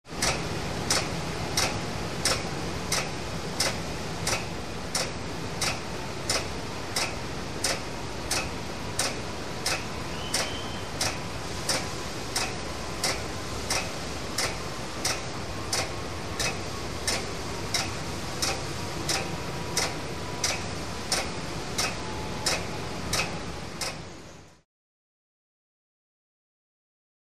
Conveyor Belt With Loud Clicks